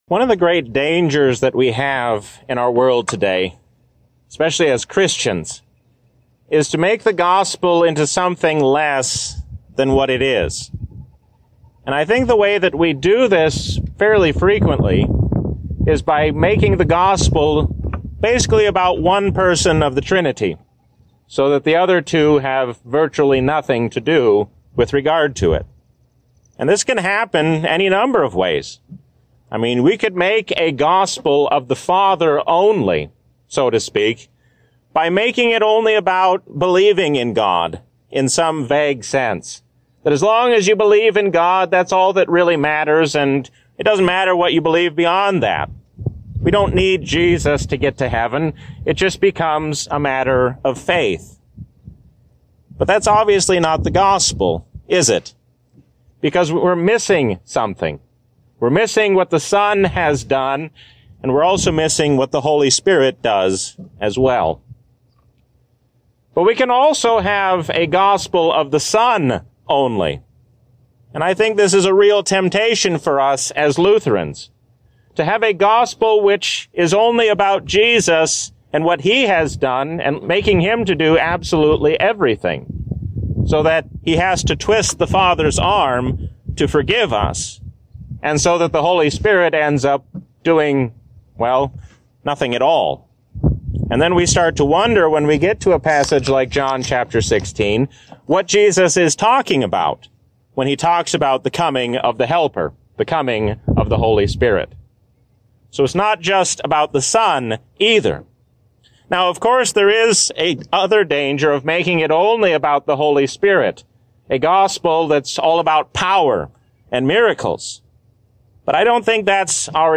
A sermon from the season "Easter 2023." Knowing who the Holy Spirit is changes how we live as Christians.